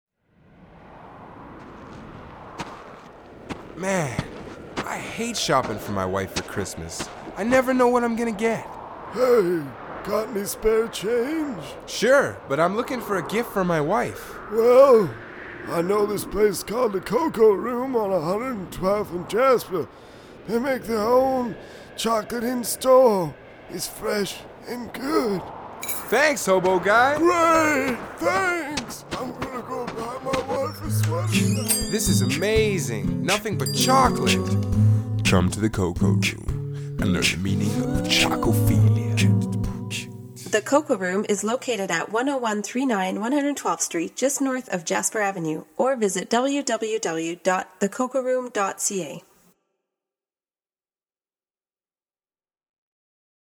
Radio Ad.